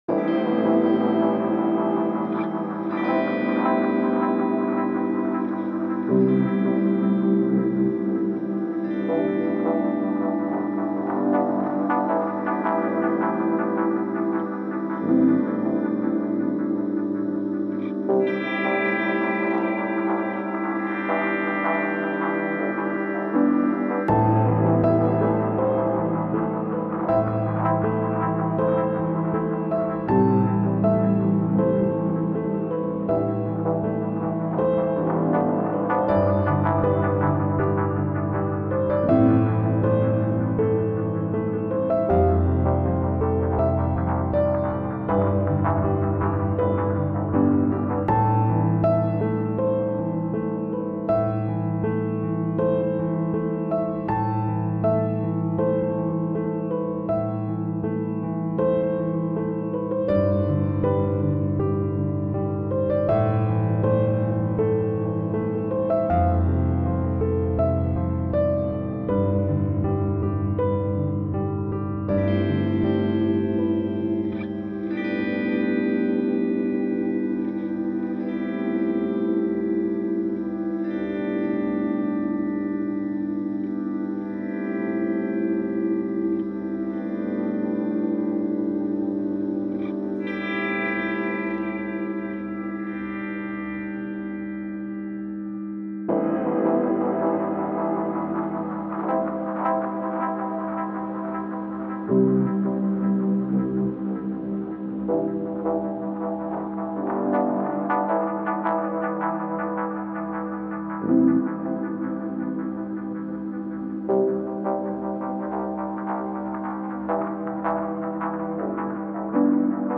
La fréquence 117,3 Hz guérit le pancréas
La-frequence-1173-Hz-guerit-le-pancreas.mp3